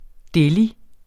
Udtale [ ˈdεli ]